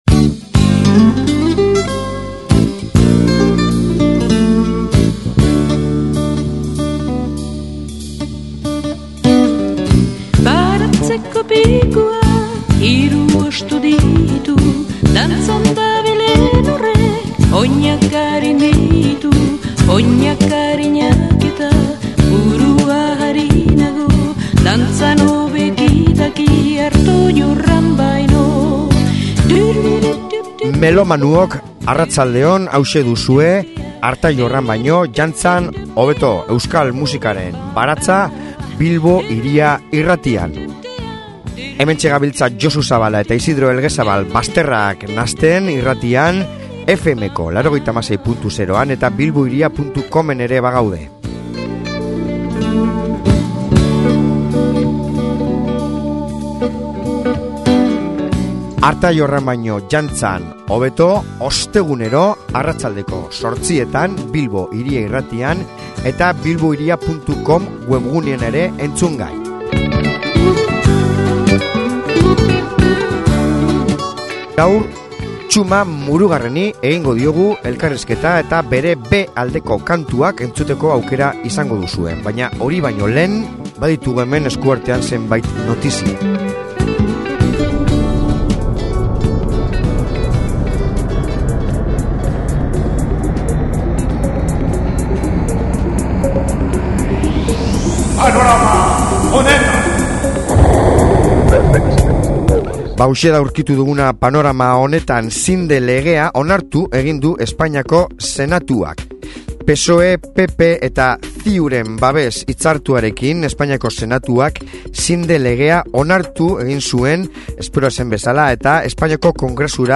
Elkarrizketa interesantea!